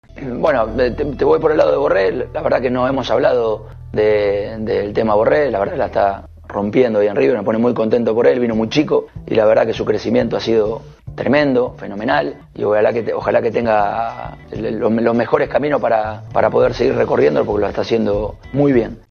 Diego Pablo Simeone, DT Atlético de Madrid - Cortesía ESPN